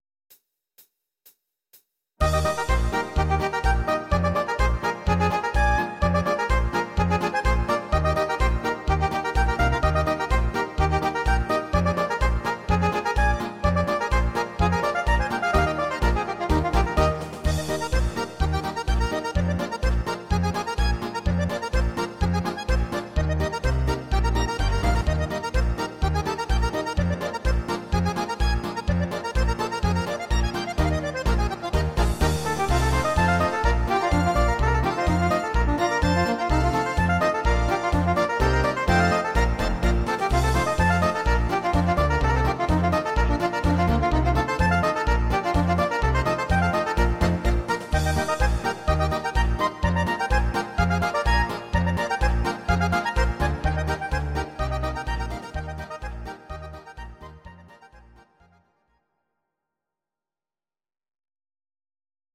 These are MP3 versions of our MIDI file catalogue.
Please note: no vocals and no karaoke included.
instr. Sopransax